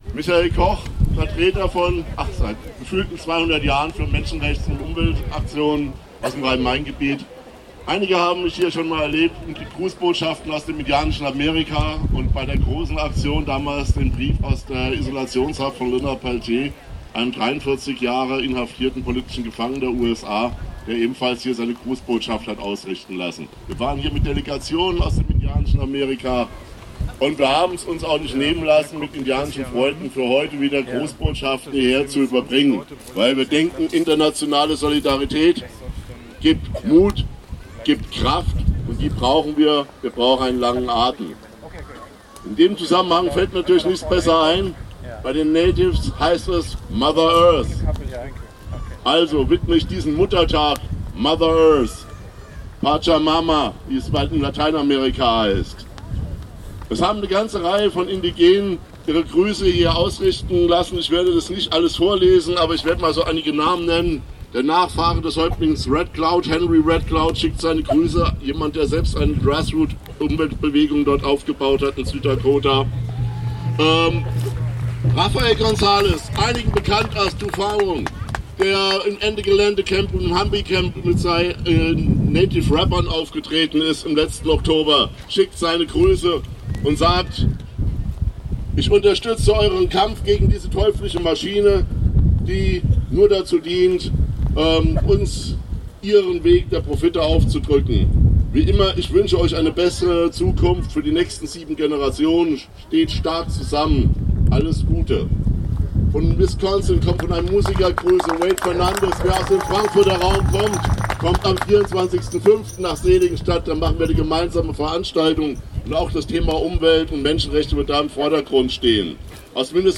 5. 'Rote Linie-Aktion' am Hambacher Wald (Audio 2/7)